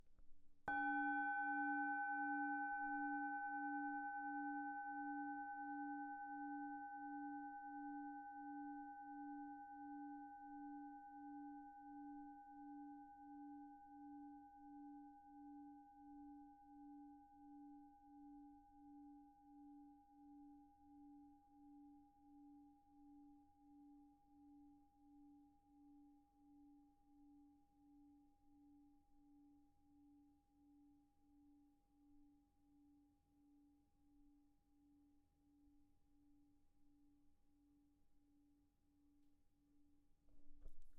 Håndfremstillet tibetansk klang- og syngeskål fra Nepal
Måler 18 cm i diameter og 9,5 cm i højden.
Grundtone D
Inkl. et underlag og en trækølle.